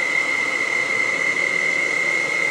saw.wav